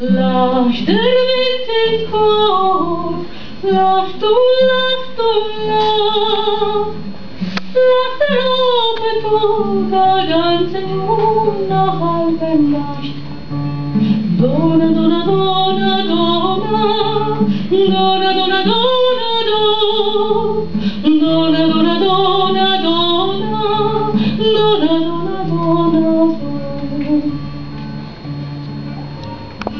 musica